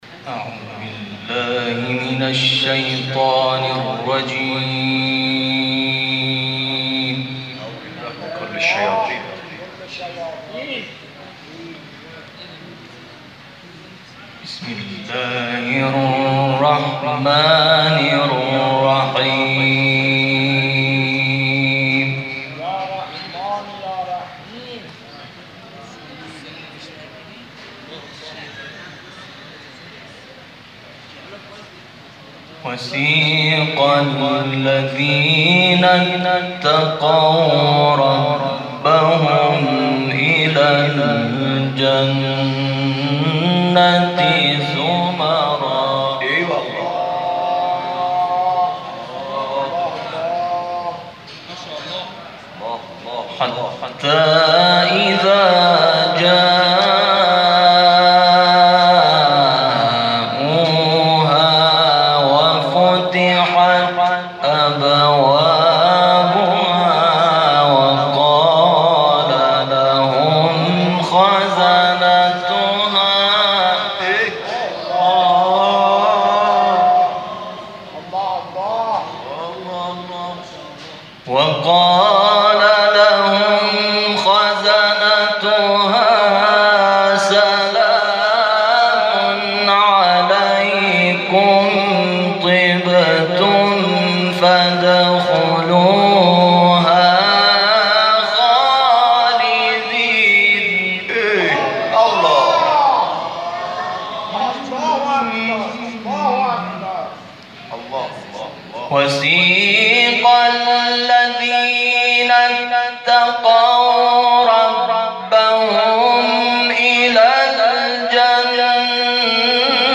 شب گذشته در مسجد شهدای تهران؛
در پایان تلاوت‌های منتخب ارائه می‌شود.